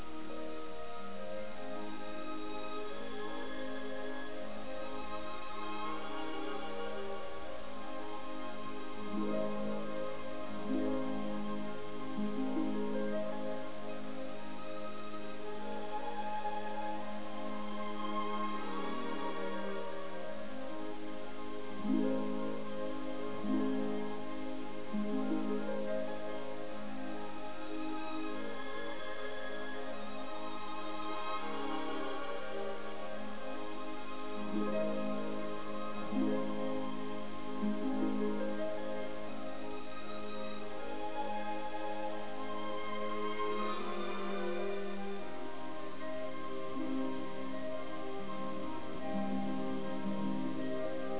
Chiaro di luna: i flauti, i clarinetti e l'arpa danno vita a una suggestiva immagine  notturna, fin a quando gli  archi ripresentano il tema del fiume.